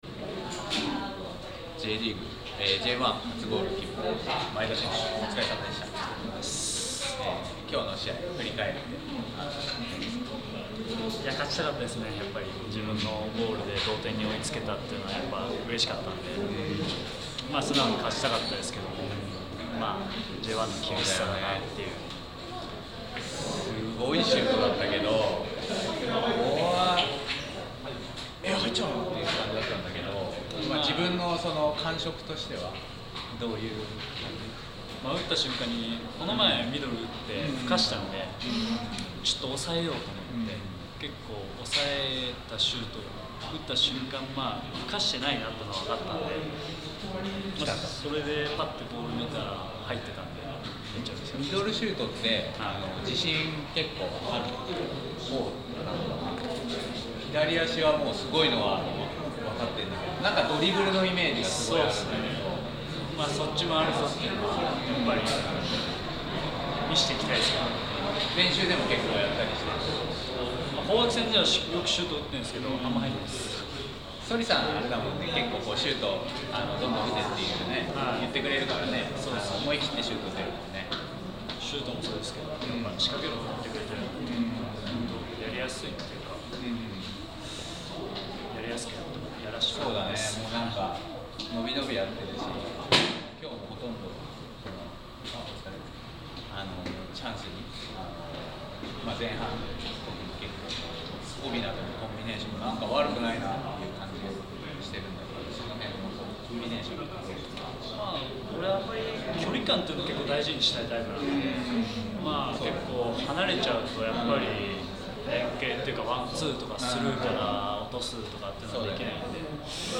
2015 J1 1st 1節 9節 vsアルビレックス新潟戦 前田 直輝インタビュー